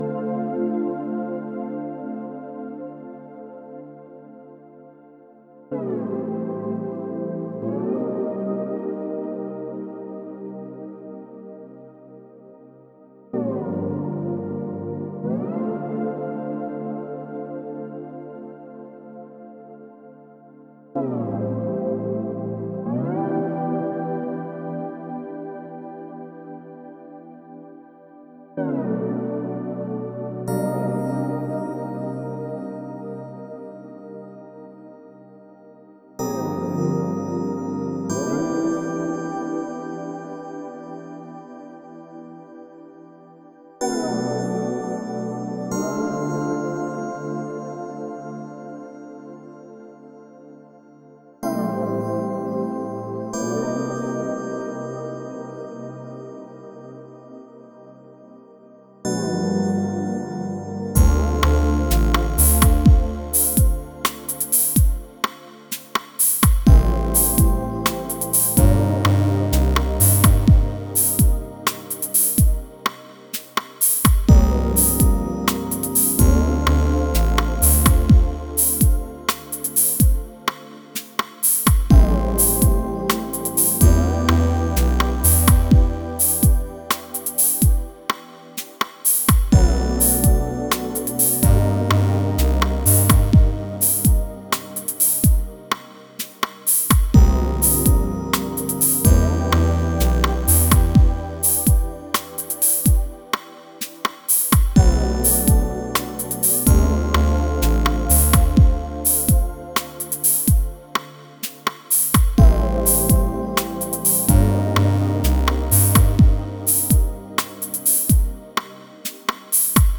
Remix of the song
Chillout
symphonic orchestra